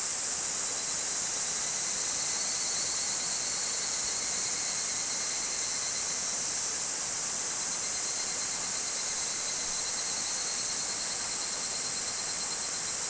cicadas_day_loop_01.wav